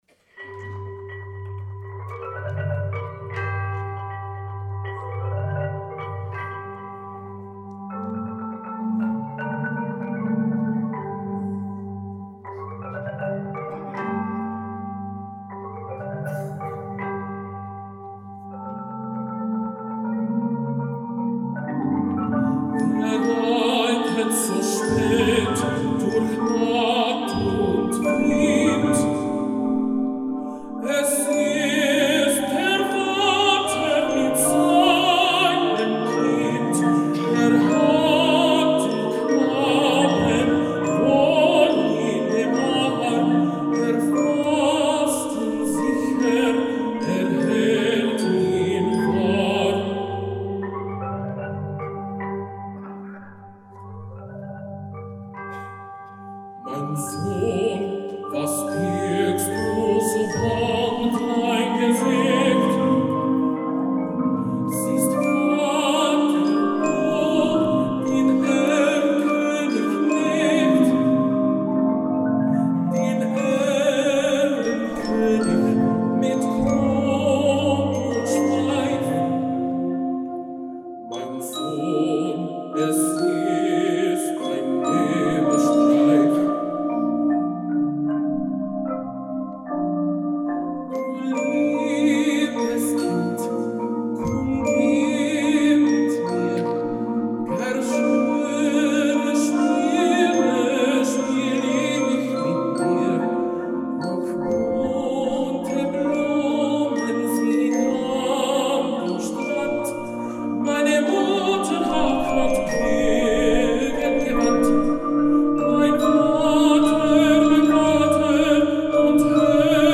West Monroe High School Band 2018-2019
Spring Concert